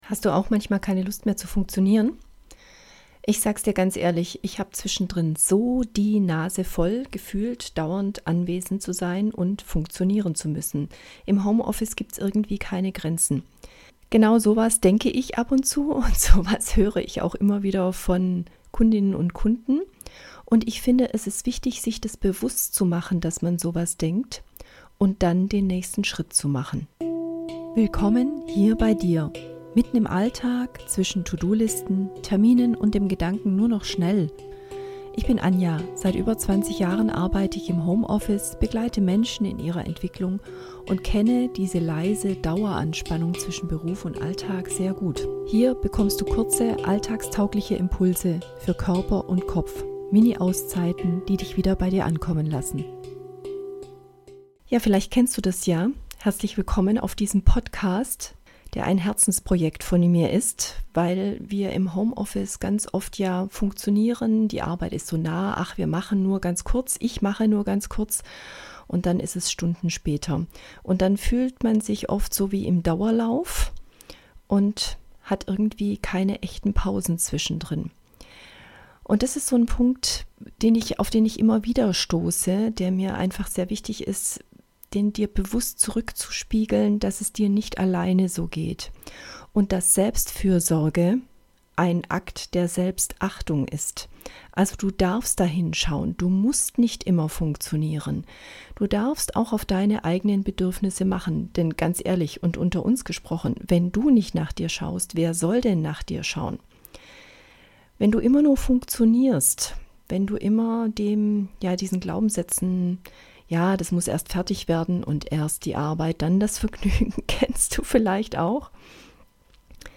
Handpan  + native american flute